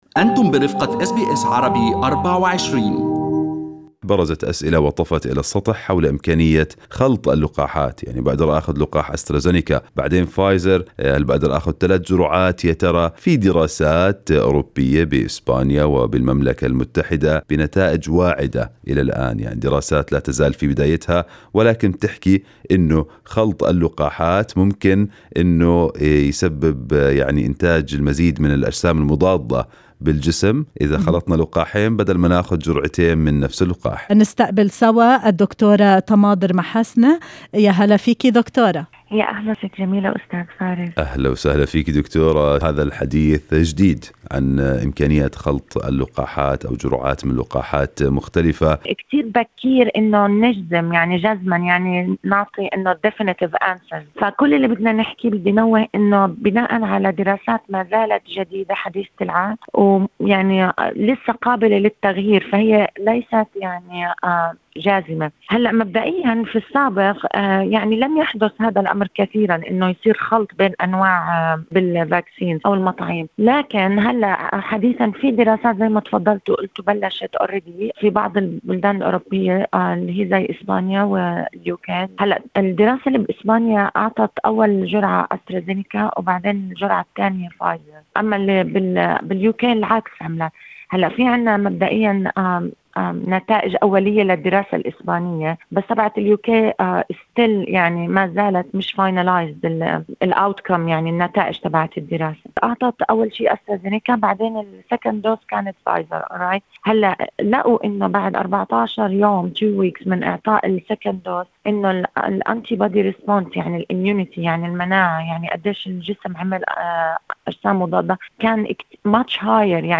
الباحثة في علم الأمراض